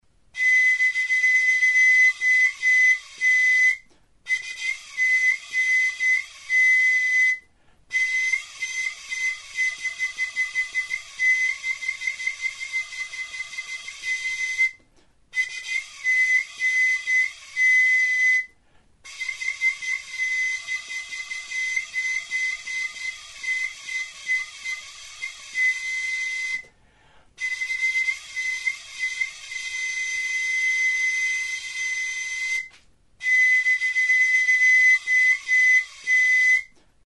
Aérophones -> Flûtes -> Á bec (á deux mains) + kena
Enregistré avec cet instrument de musique.
Flauta honek banbuzko hodia du, eta goiko muturrean flauta motako ahokoa. Tonu aldaketarako 2 zulo ditu aurrekaldean. Beheko muturra itxia du.